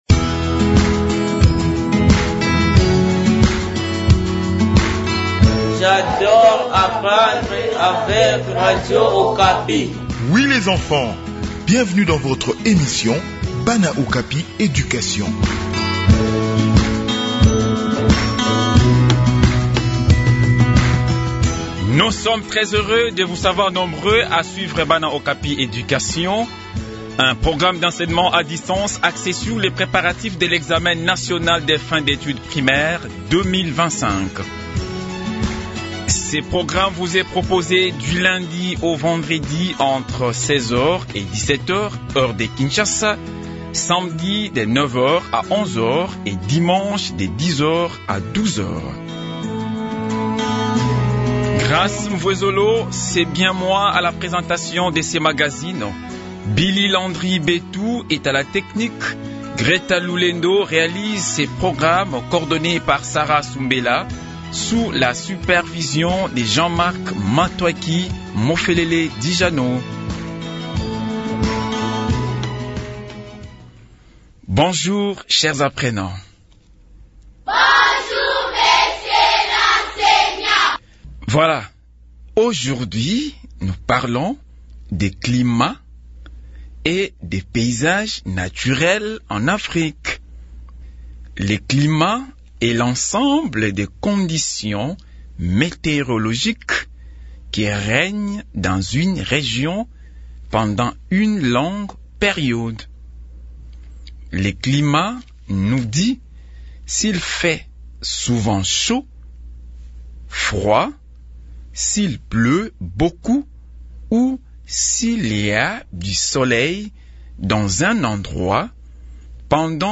Préparation aux examens nationaux : leçon de géographie sur les climats en Afrique